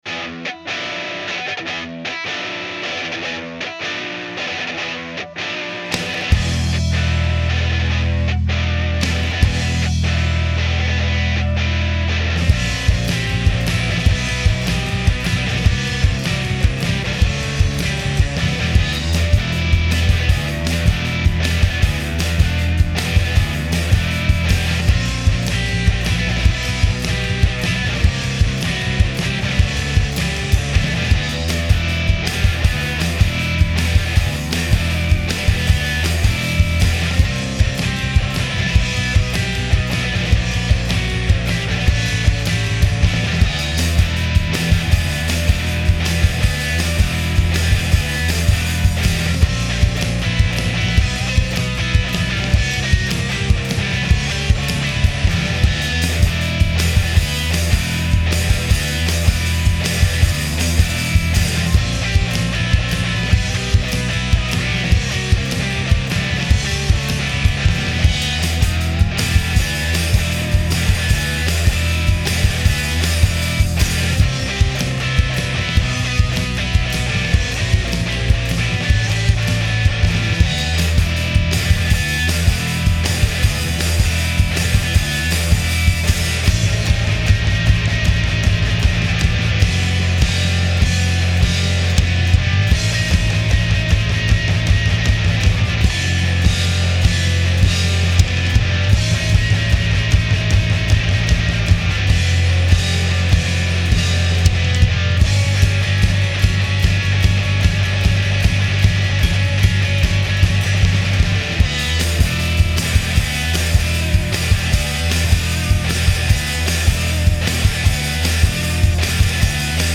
at our secret rehearsal space in Philly